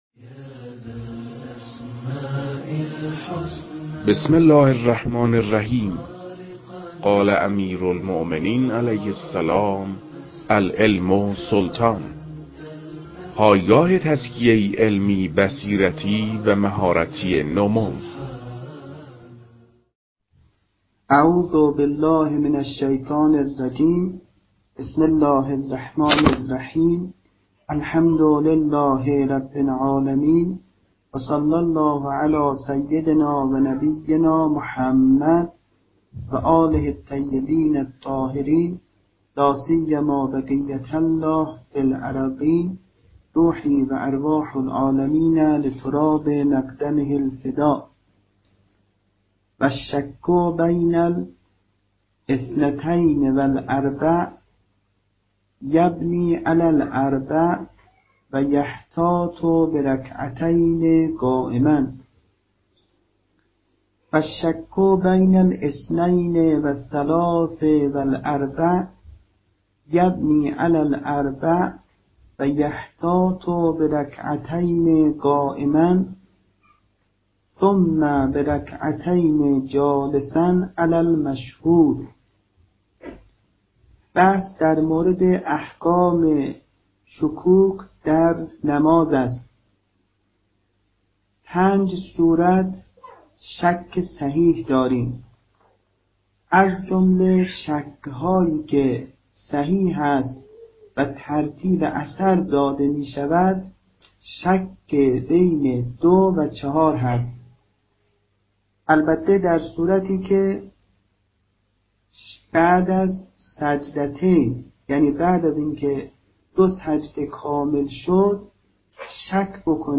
این فایل، تدریس بخشی از کتاب شرح لمعه